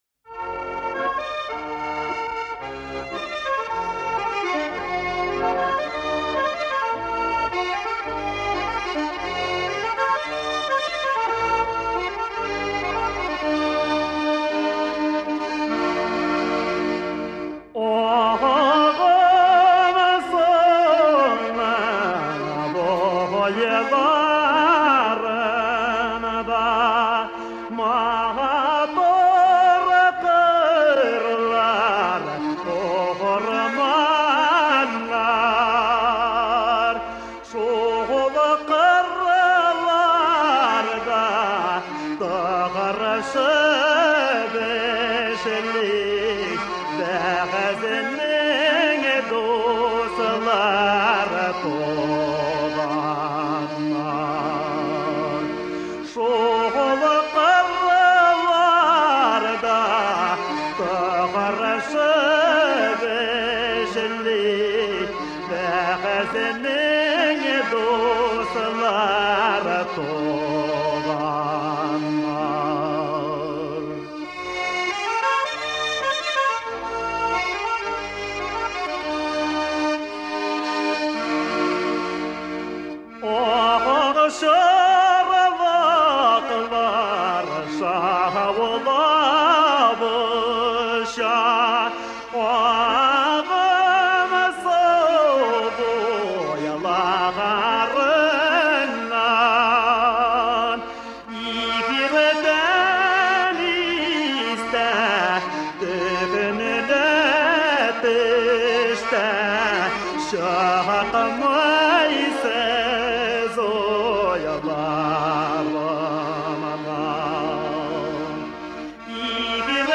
Lidová hudba